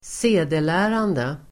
Ladda ner uttalet
Uttal: [²s'e:delä:rande]